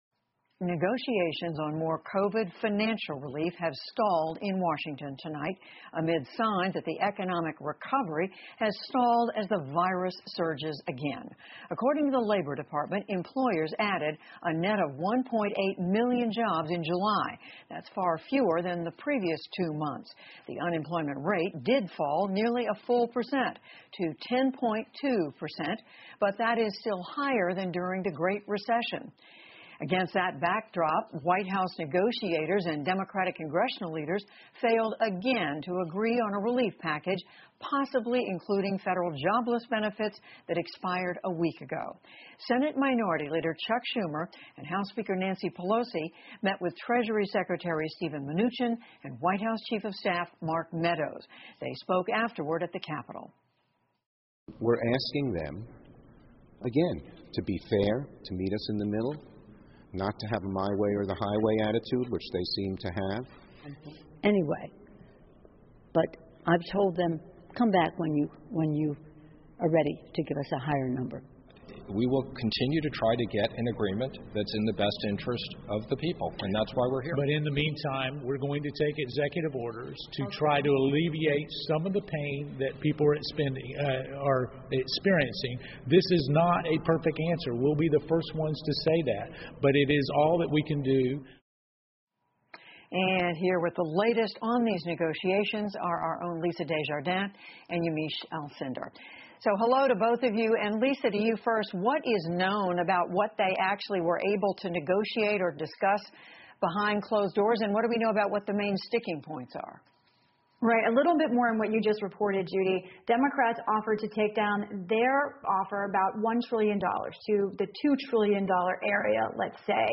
PBS高端访谈:财政预算谈判陷入僵局 听力文件下载—在线英语听力室